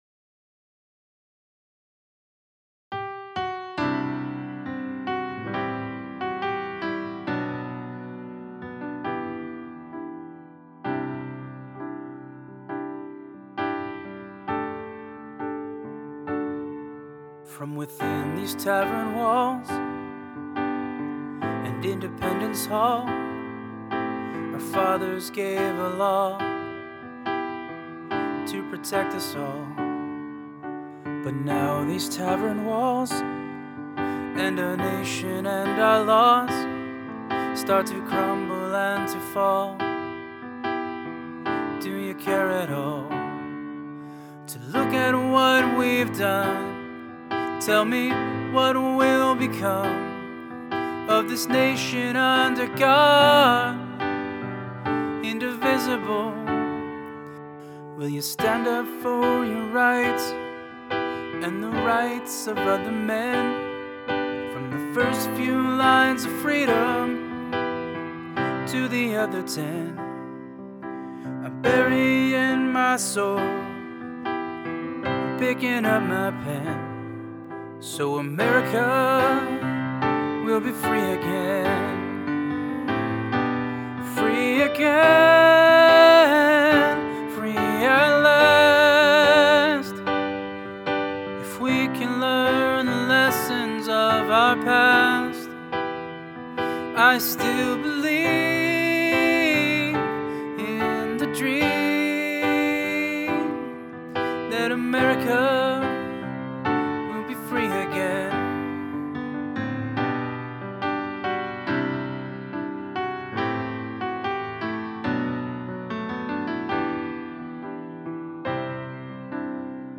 Voicing/Instrumentation: Vocal Solo